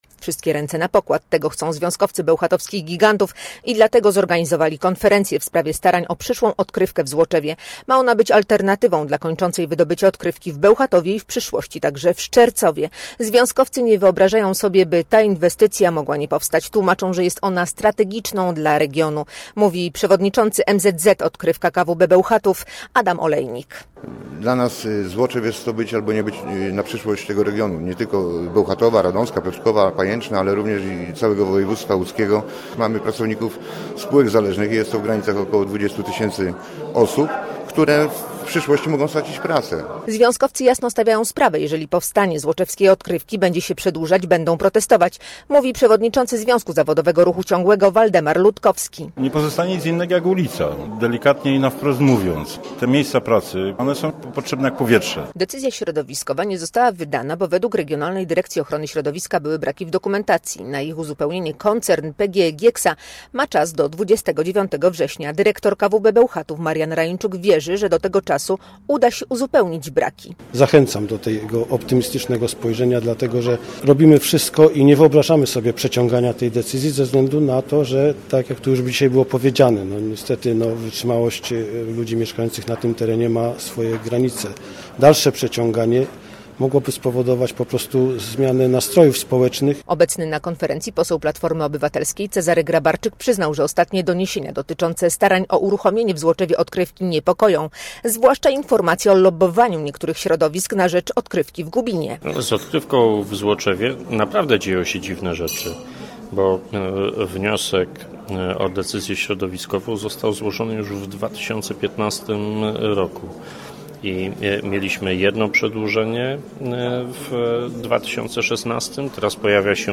Posłuchaj relacji: Nazwa Plik Autor Walka o Złoczew audio (m4a) audio (oga) Warto przeczytać Dzień Konwalii.